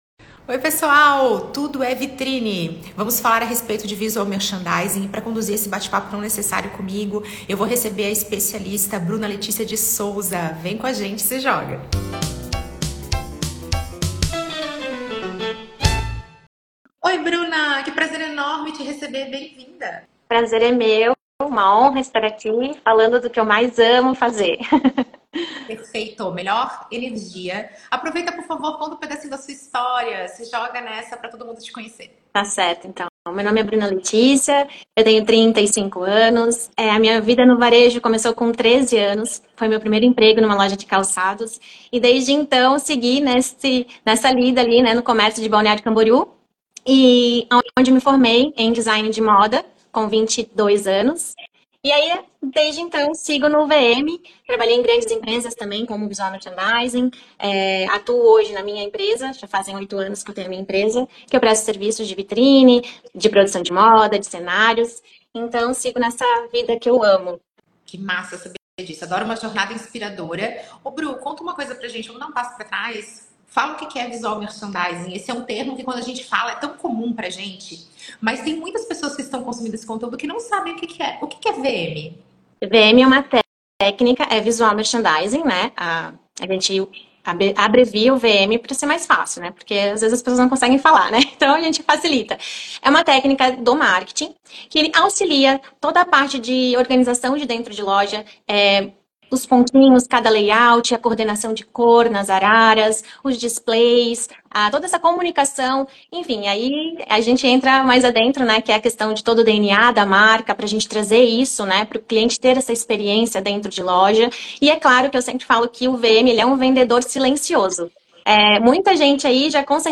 Como criar e implantar estratégias de Visual Merchandising para criar experiências memoráveis, gerar mais negócios e fidelizar mais clientes. Bate-papo com a especialista